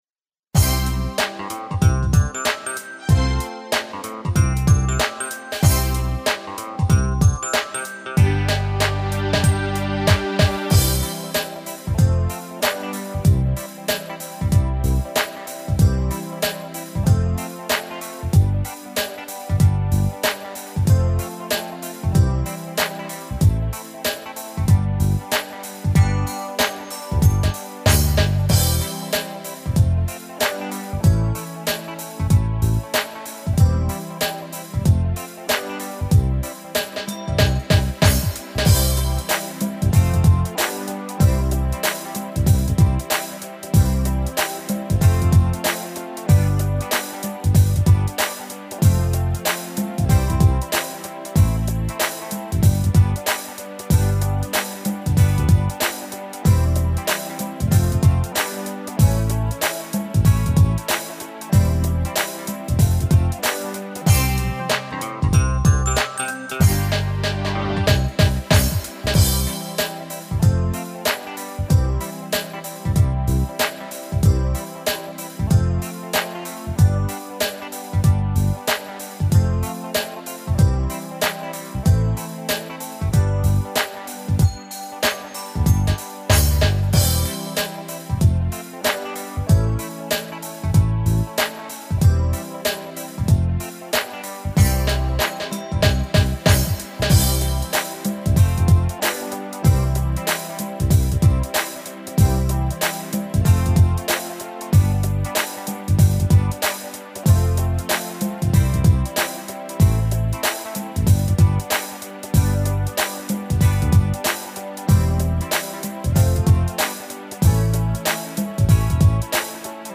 MP3 of the background accompaniment